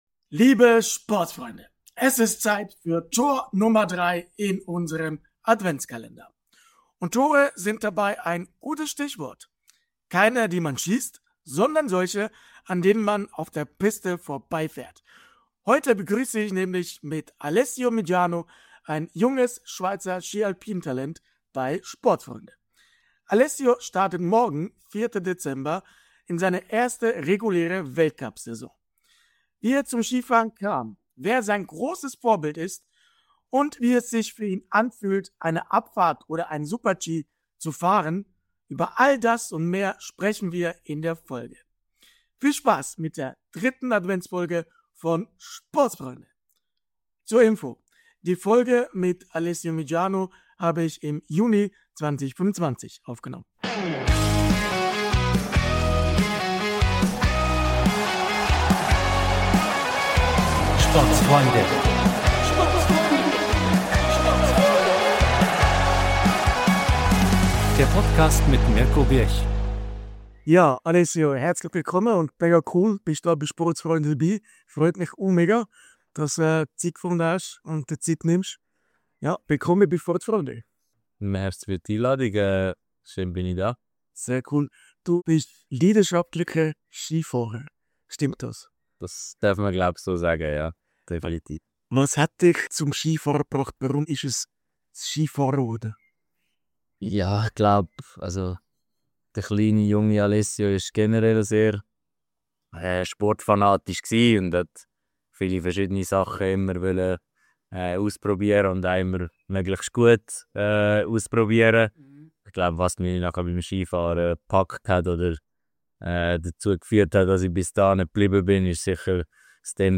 Spezialfolge auf Schweizerdeutsch!